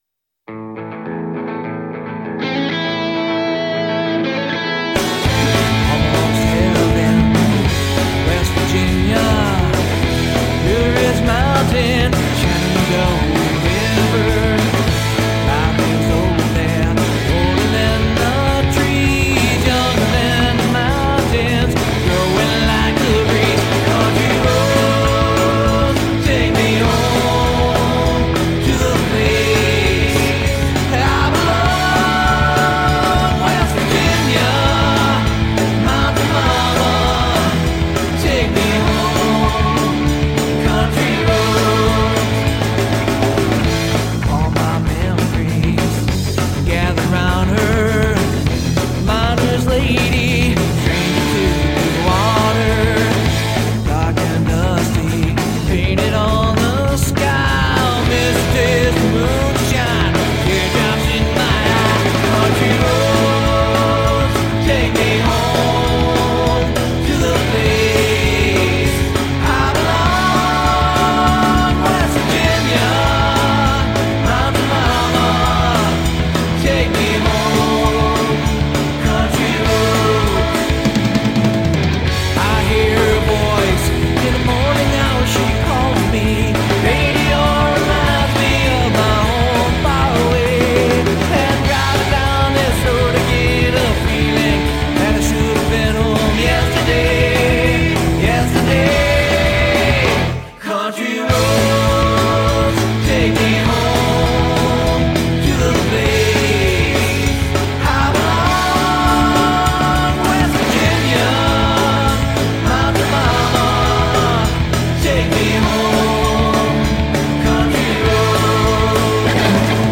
He played lead guitar and sang vocals.